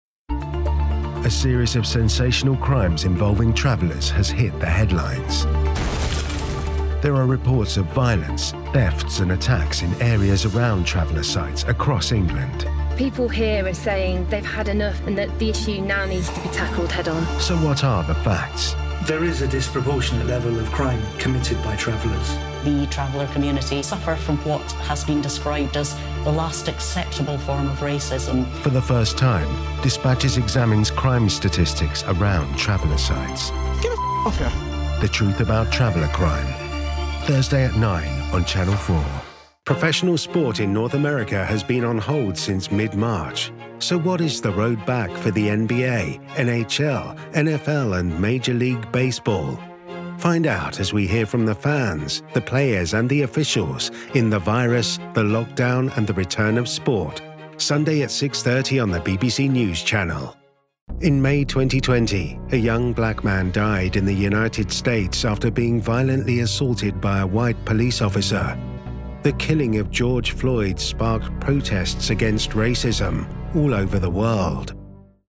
Engels (Brits)
Diep, Natuurlijk, Toegankelijk, Vriendelijk, Warm
Corporate